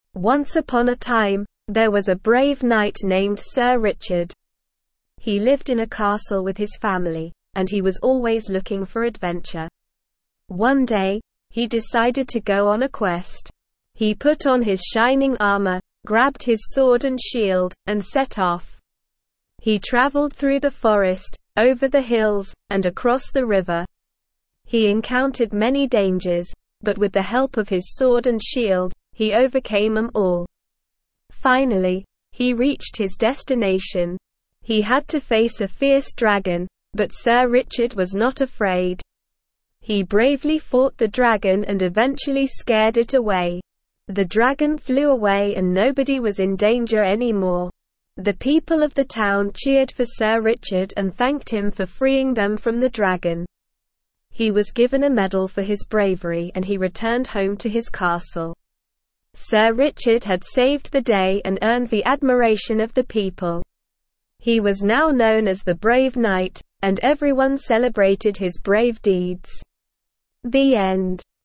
Slow
ESL-Short-Stories-for-Kids-SLOW-reading-The-Brave-Knight.mp3